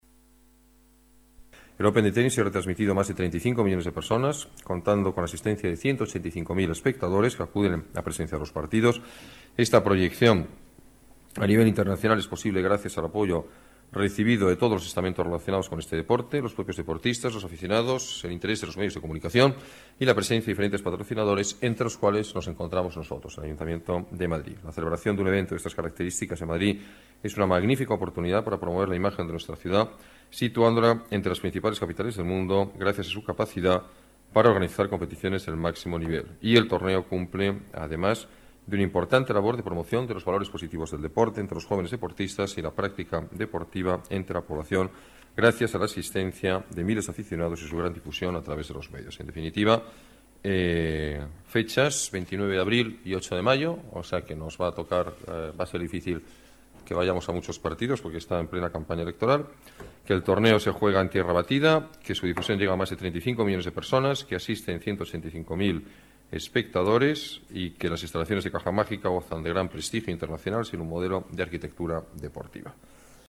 Nueva ventana:Declaraciones alcalde, Alberto Ruiz-Gallardón: Open Tenis Madrid